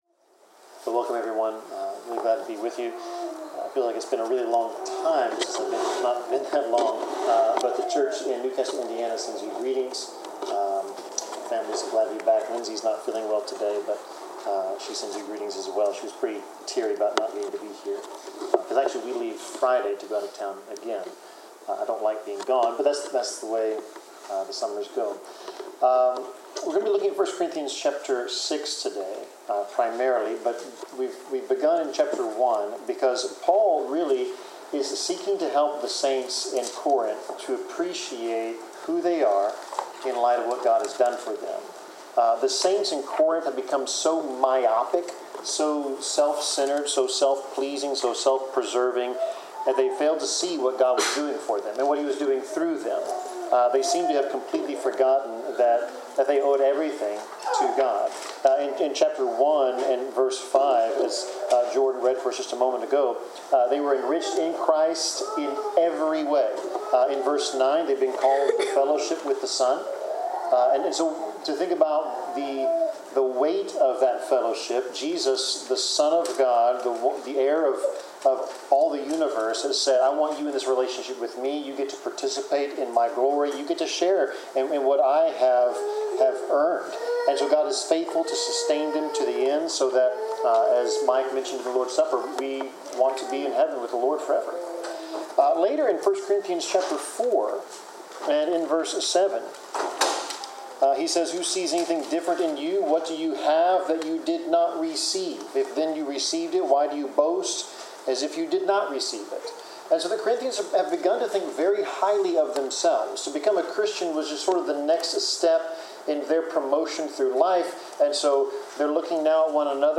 Passage: 1 Corinthians 6:9-20 Service Type: Sermon